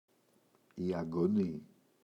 αγκωνή, η [aŋgo’ni] – ΔΠΗ